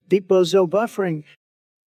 deep-bozo-buffering.wav